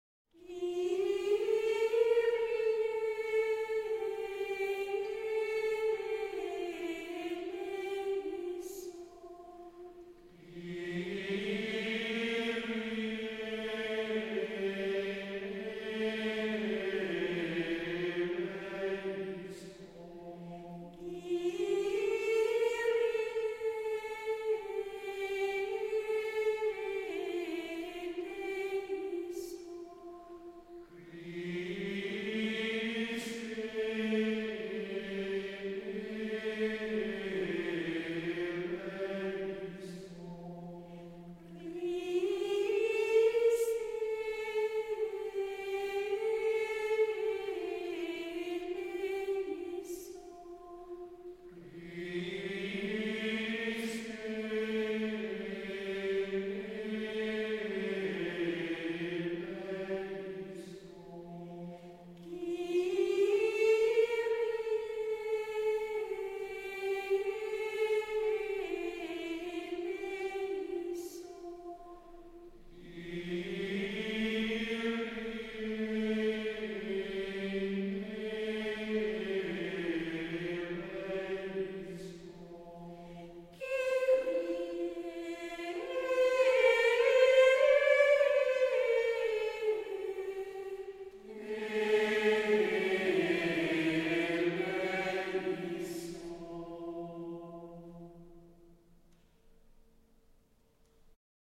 Het Kyrië, uitgevoerd door het Gregoriaans koor van Watou.
Schola Cum Jubilo Watou
Gregoriaans koor
Klik op de afbeelding om het Kyrië gezongen door dit koor te beluisteren.